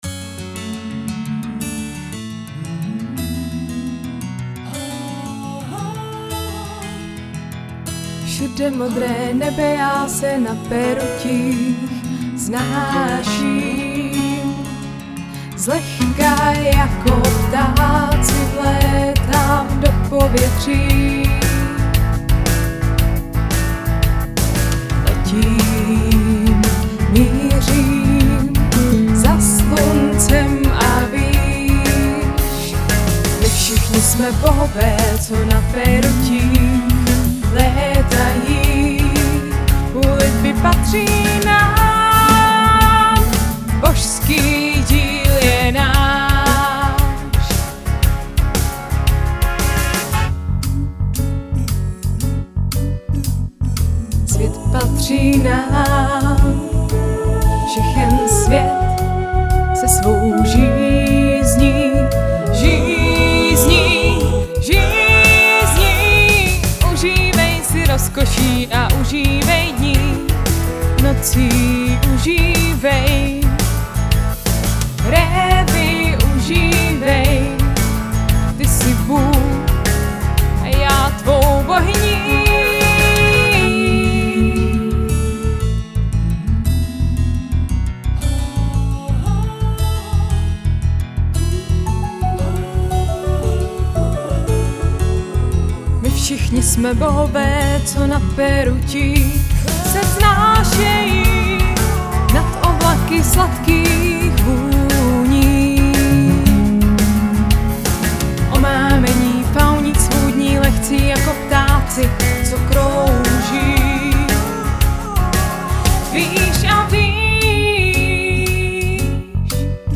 muzikál o světě, který se mění